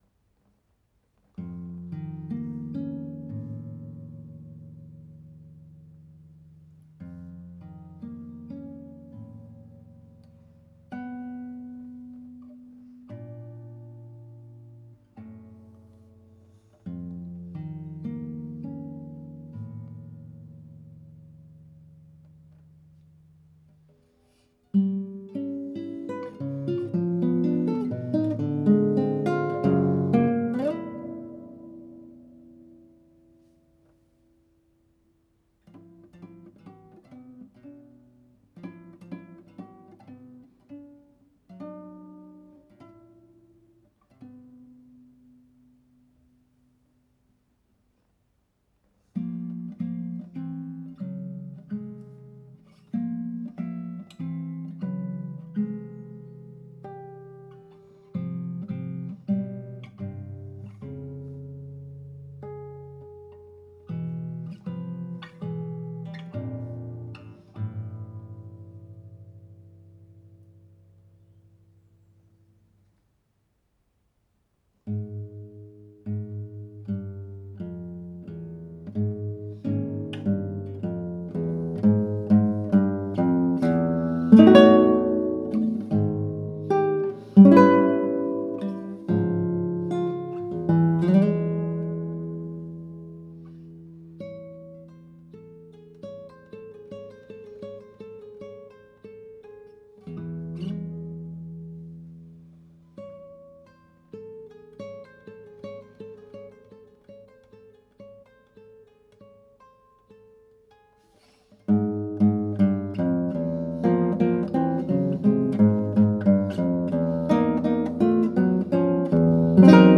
Homage to Takemitsu (for guitar, 2022) SCORE |